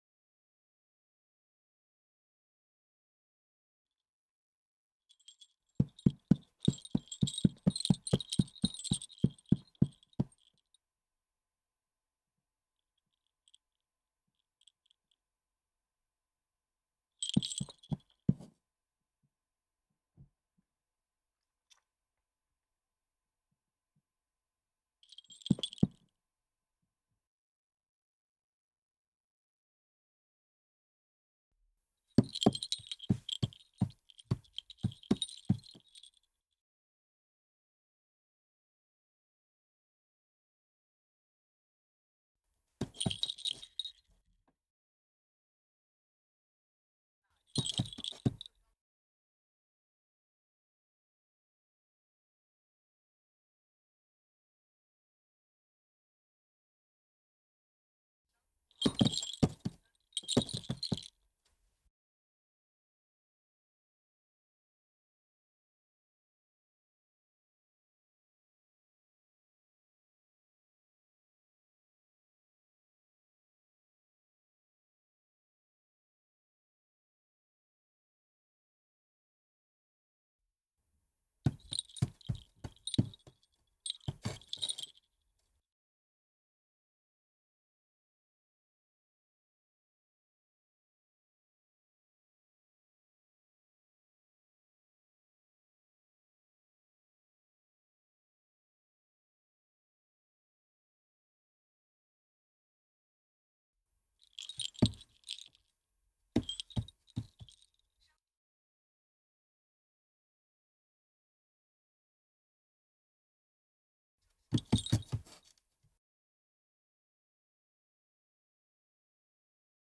SFX_Scene03_Dog.ogg